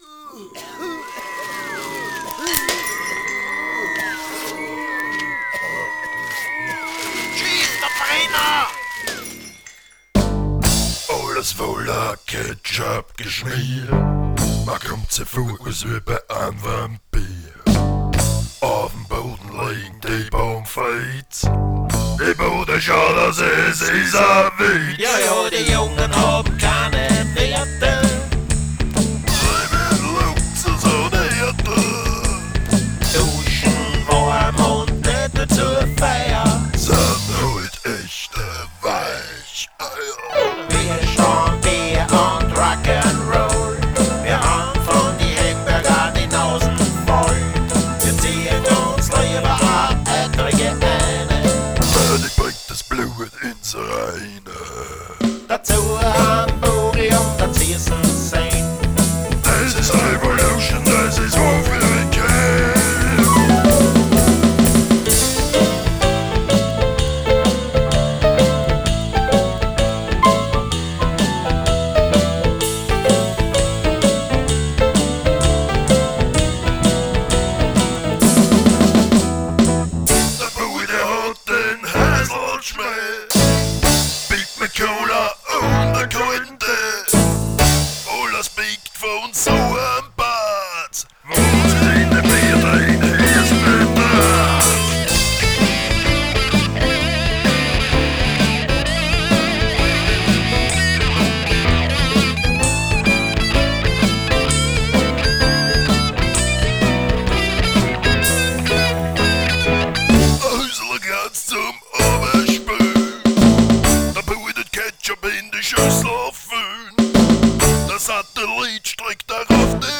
Genre: Freie Musik - Rock'n'Roll